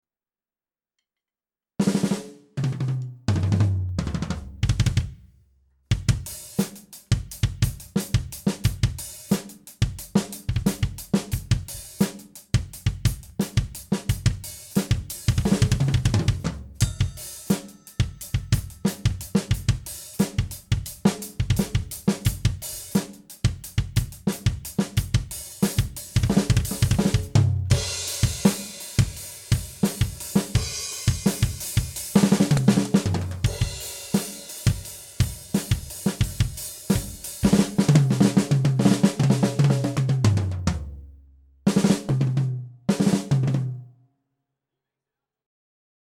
Drum recording EQ help
This is just a quick drum recording i did and put some work into.
I know some stuff is low like the snare, just more curious about the EQ on it. mainly the bass drum.